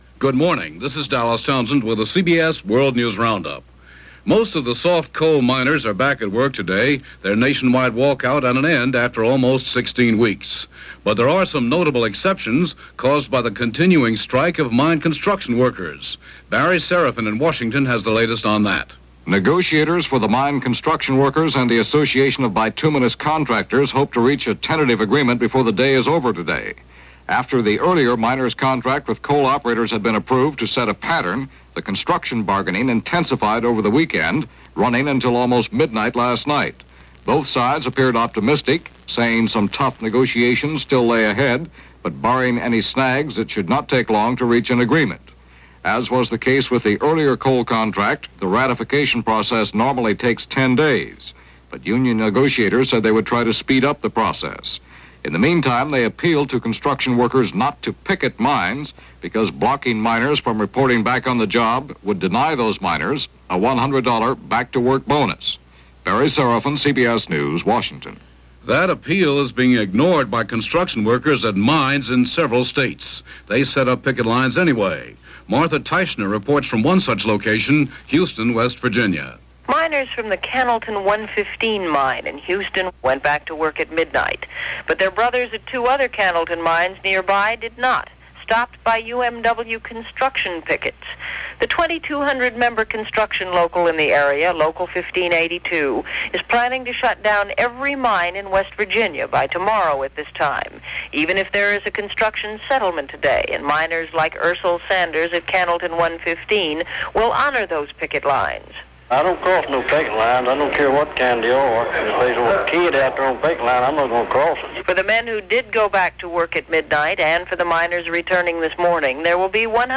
March 27, 1978 - The Coal Miners Strike - A Ceasefire In Lebanon - Storming The Narita Airport - news for this day in 1978.